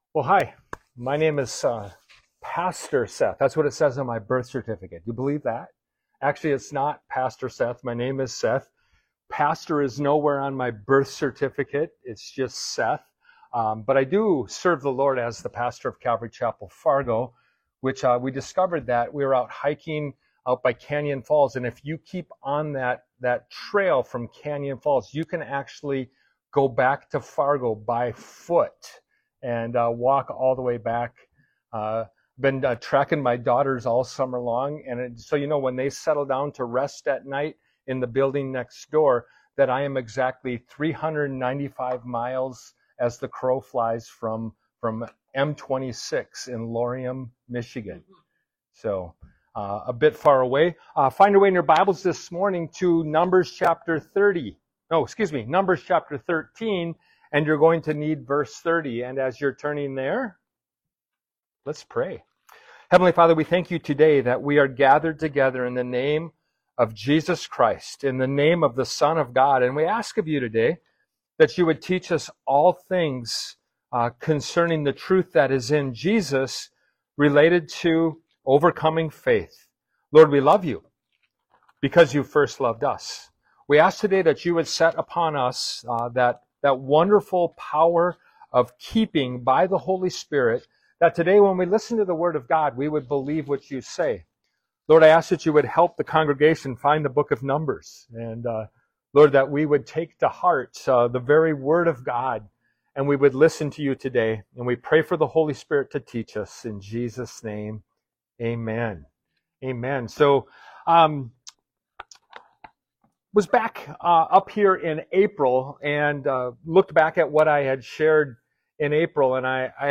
Series: Guest Speaker Service Type: Sunday Morning « “But God” Genesis 50 “Make Way For The Lord” Ministry of Jesus Part 1 »